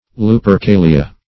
Search Result for " lupercalia" : The Collaborative International Dictionary of English v.0.48: Lupercalia \Lu`per*ca"li*a\, n. pl.